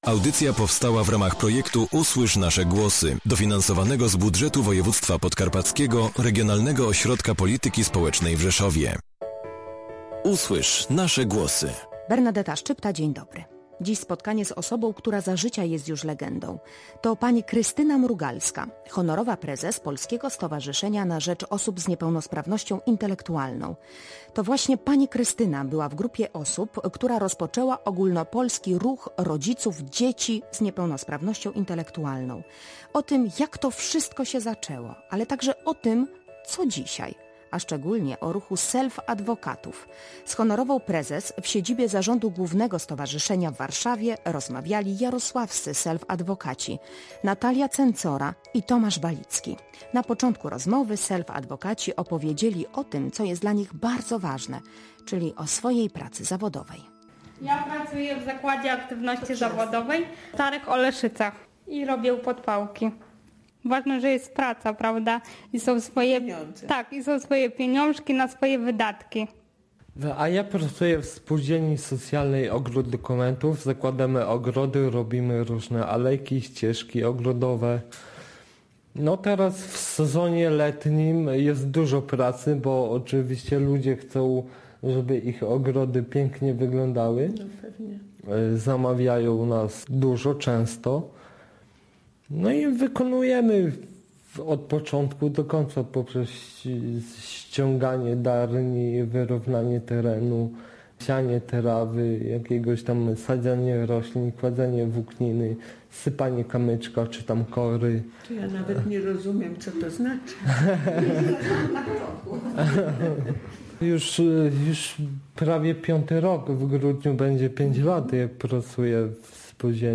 wywiad przeprowadzili self-adwokaci